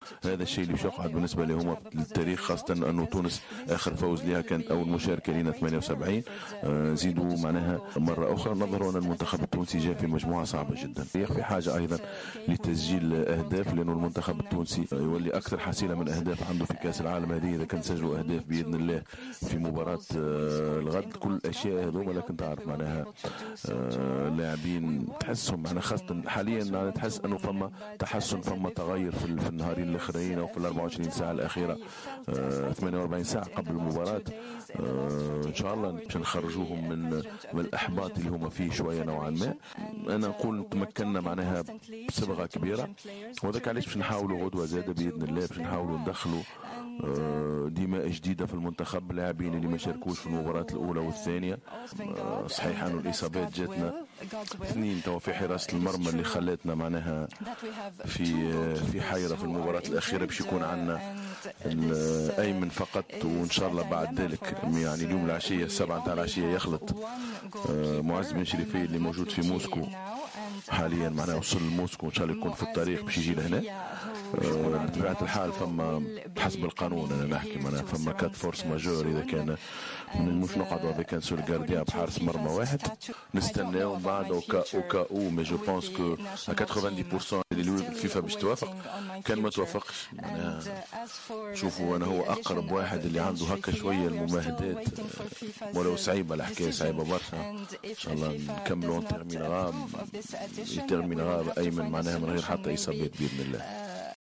تحدث مدرب المنتخب الوطني نبيل معلول خلال الندوة الصحفية التي عقدها اليوم الإربعاء 27 جوان 2018 صحبة اللاعب إلياس السخيري عن المواجهة المرتقبة أمام منتخب بنما غدا الخميس 28 جوان 2018 في اطار الجولة الثالثة من منافسات المجموعة السابعة للمونديال.
نبيل معلول : مدرب المنتخب الوطني